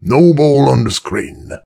brickmove07.ogg